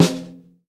snare 8.wav